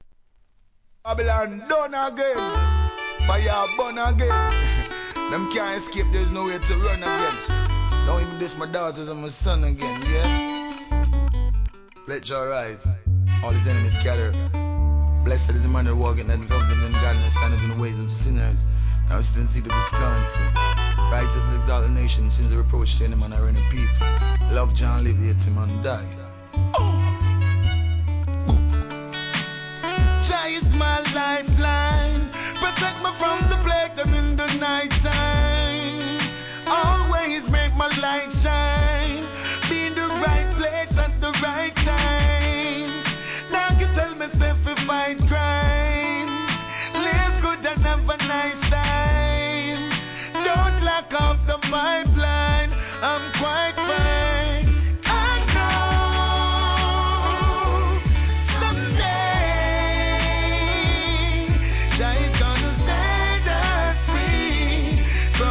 REGGAE
アコースティックの美しいメロディーで好ラスタ・チュ〜ン!